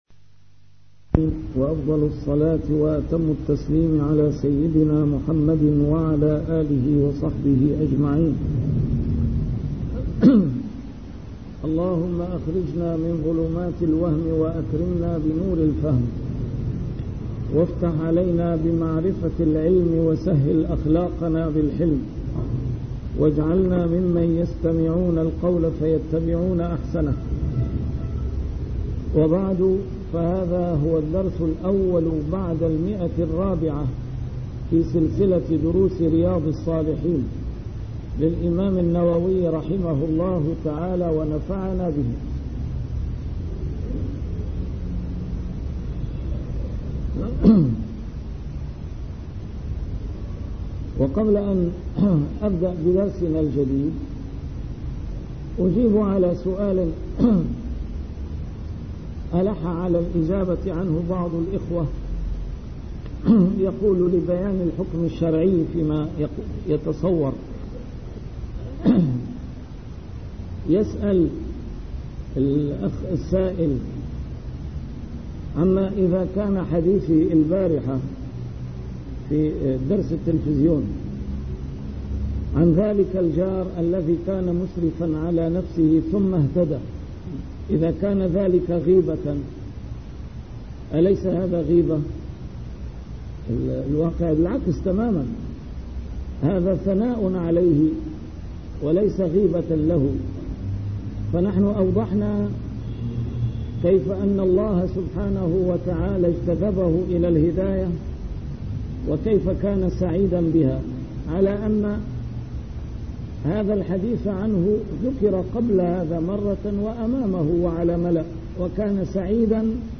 A MARTYR SCHOLAR: IMAM MUHAMMAD SAEED RAMADAN AL-BOUTI - الدروس العلمية - شرح كتاب رياض الصالحين - 401- شرح رياض الصالحين: بر الوالدين وصلة الأرحام